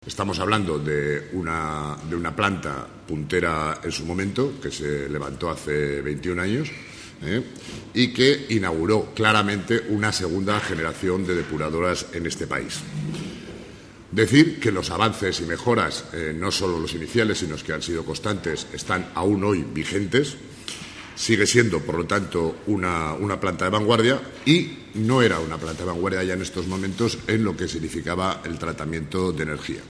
El consejero de Urbanismo, Infraestructuras, Equipamientos y Vivienda, Carlos Pérez Anadón, profundizaba en esta reflexión.